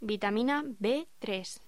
Locución: Vitamina B3
voz